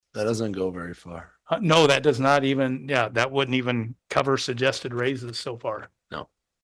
District 2 Supervisor Mike Andersen says that will add to the already challenging task of preparing a county-wide budget, which includes more than 20 departments.